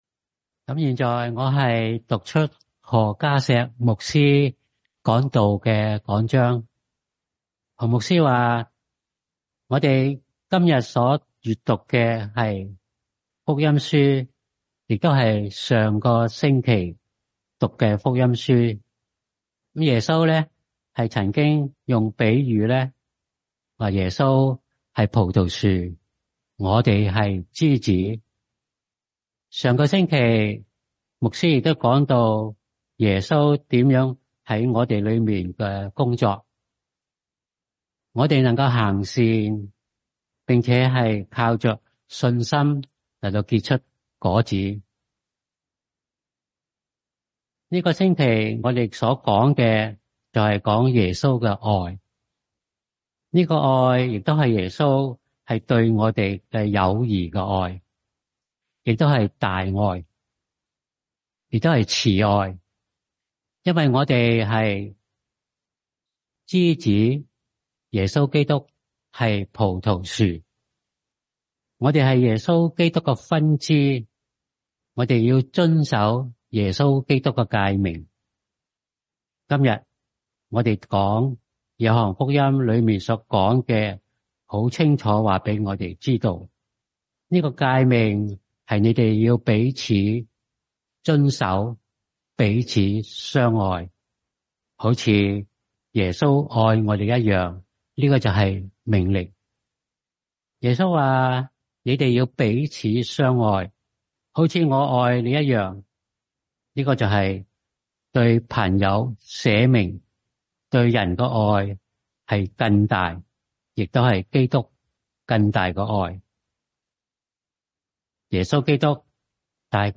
Chinese Service – The Love of Jesus (John 15:9-17)